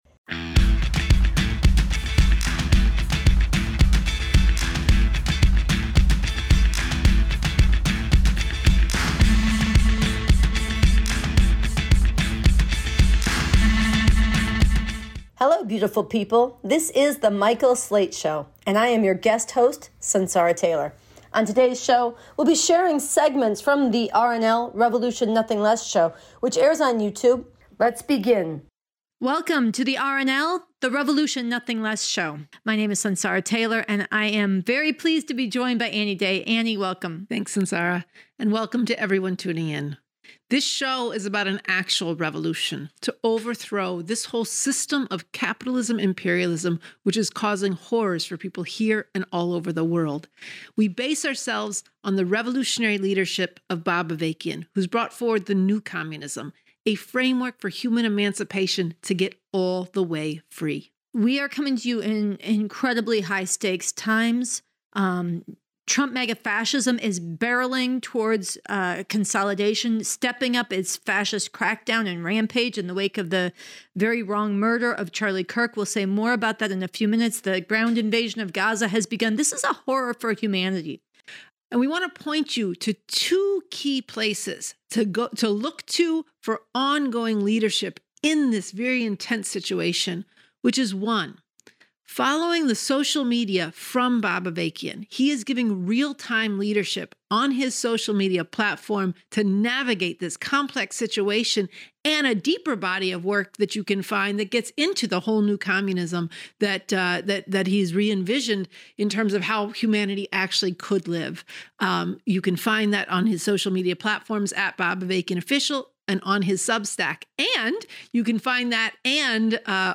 Program Type: Weekly Program